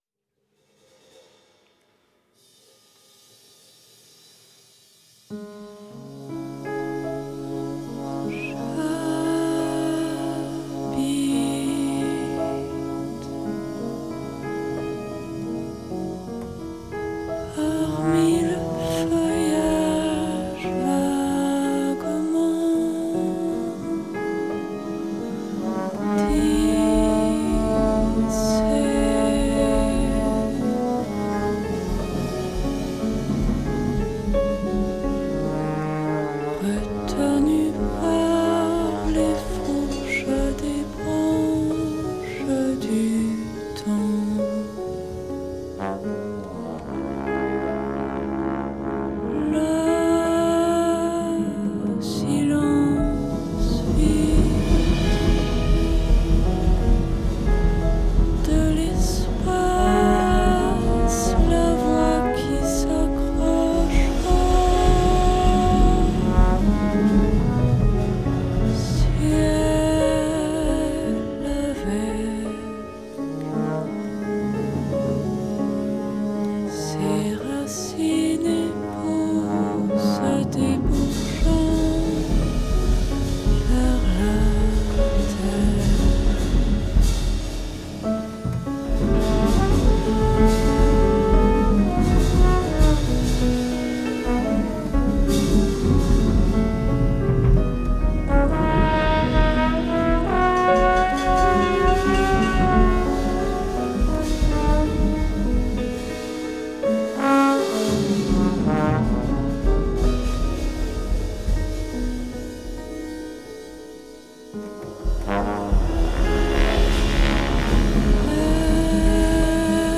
drums
trombone